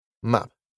イギリス英語では「A」の音はカタカナの「」に近い音で発音することが多いです。
たとえばmapやbackは「マップ」「バック」と発音します。
🇬🇧 map（mæp）